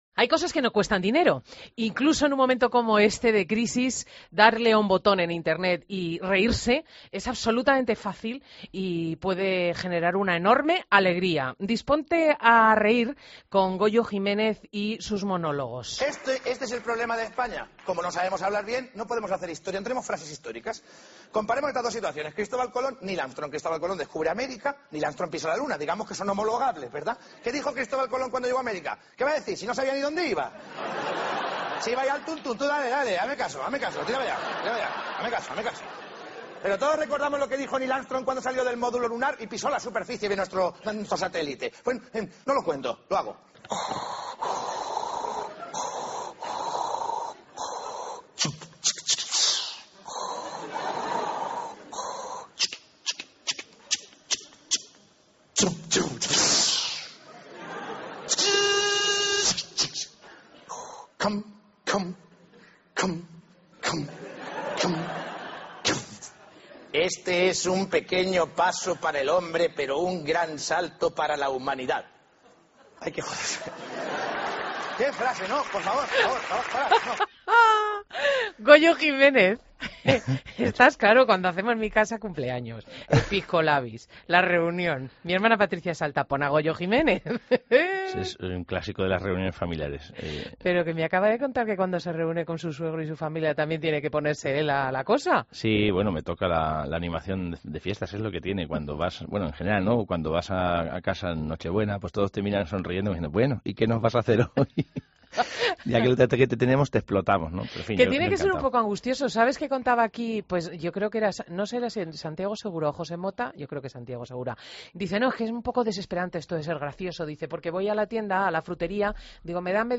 Entrevista a Goyo Jiménez en "Fin de Semana"